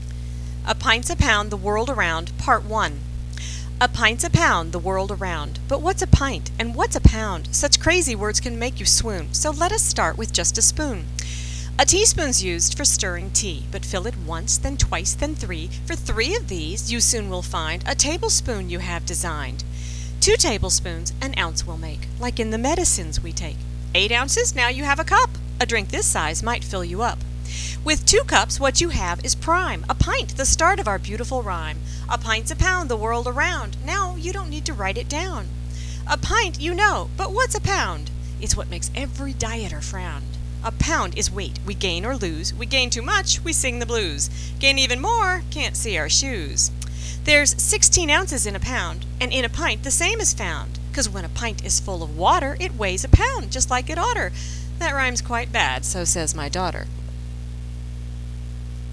This ditty, repeated regularly, will cement useful measurements and their equivalents into your child's memory. You'll notice that the audio link stops 10 lines before the end.
First, my audio recorder only permits a 60-second recording.